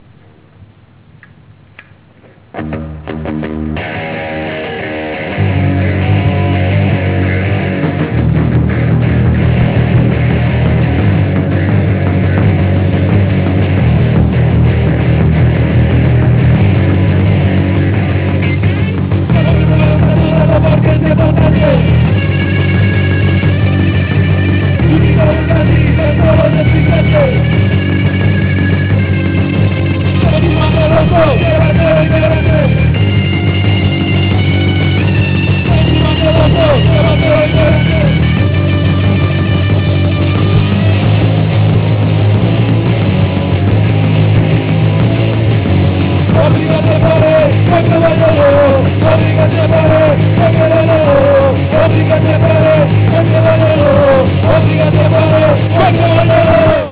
ska-oi anteprima